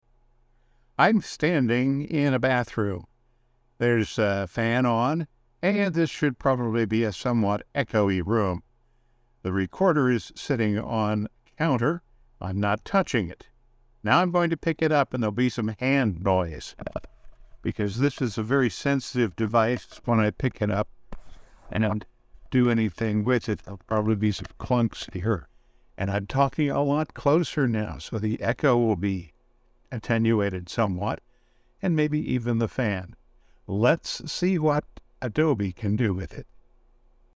Adobe has a free service that can be used to improve this kind of sound using the company’s Sensei artificial intelligence.